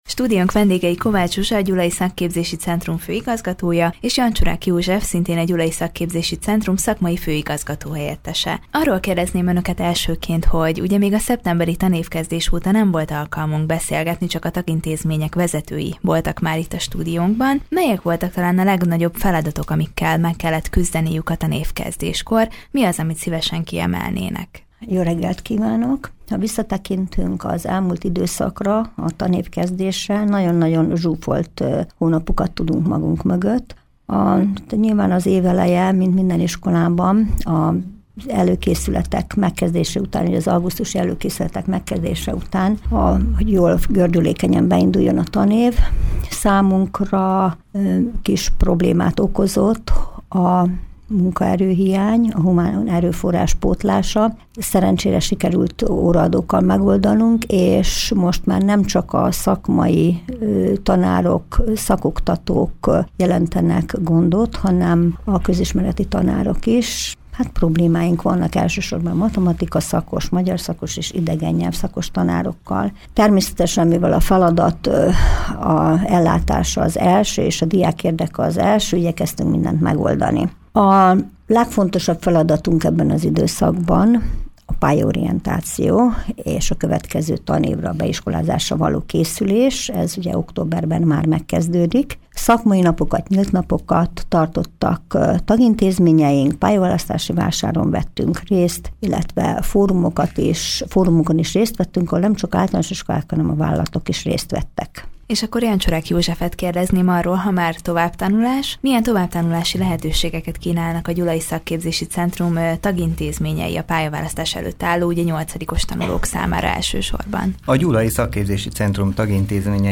Nagyon zsúfolt hónapok állnak a Gyulai Szakképzési Centrum mögött. A beiskolázásra való készülés mellett szakmai- és nyílt napokat tartottak a tagintézmények, részt vettek a pályaválasztási vásáron és különböző fórumokon is. Ezekkel kapcsolatban voltak a Körös Hírcentrum stúdiójának vendégei